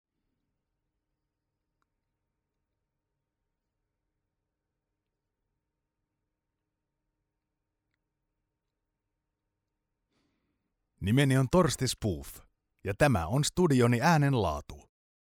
Male
30s, 40s, 50s
Approachable, Authoritative, Confident, Conversational, Corporate, Energetic, Engaging, Friendly, Natural, Reassuring, Smooth, Warm
Commercial, Narration, IVR or Phone Messaging, Corporate, Character, Educational, E-Learning, Documentary, Explainer, Training
Microphone: Sennheiser, Erlund